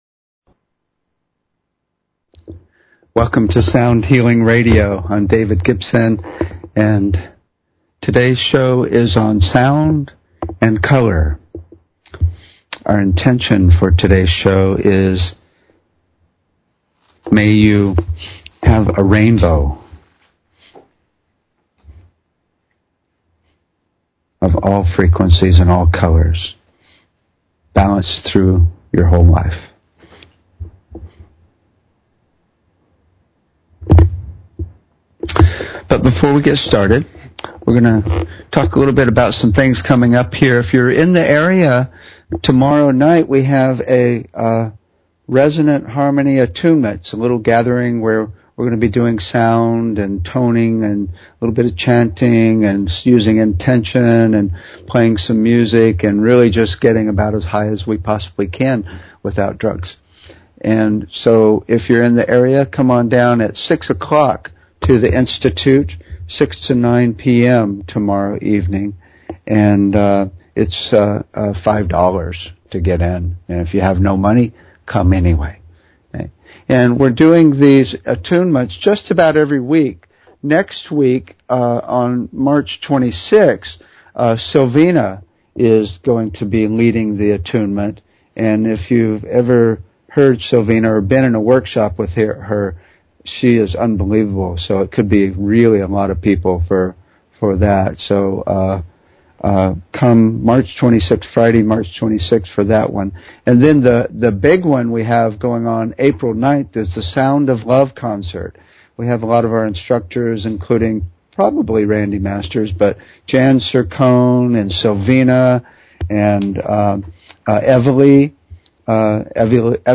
Talk Show Episode, Audio Podcast, Sound_Healing and Courtesy of BBS Radio on , show guests , about , categorized as
THE SOUND OF COLORS We will discuss ways to correlate sound to color, and play with the sound of various colors. We'll then apply those colors to various chakras and parts of the body using various intentions.
The show is a sound combination of discussion and experience including the following topics: Toning, Chanting and Overtone Singing - Root Frequency Entrainment - Sound to Improve Learning -Disabilities - Using Sound to Connect to Spirit - Tuning Fork Treatments - Voice Analysis Technologies - Chakra Balancing - Sound to Induce Desired S